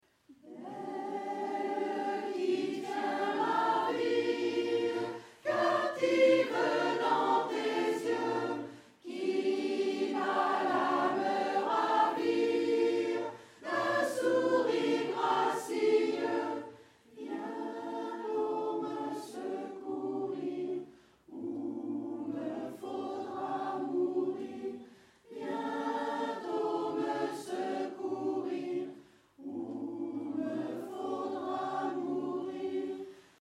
Répertoire chorale 2020-2021